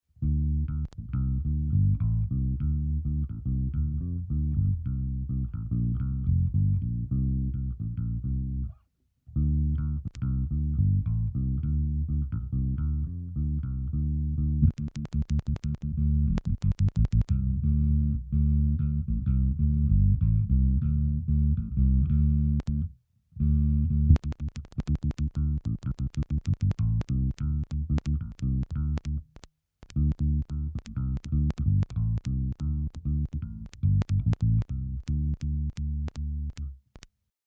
Am Anfang knackst es ein mal. Dann schalte ich auf einen anderen Snapshot und wieder zurück. Am Ende hört man nur noch das Knacksen.
Das Knacksen ergibt sich sowohl bei der Aufnahme über USB, also auch bei der ganz normalen Ausgabe über die Outs.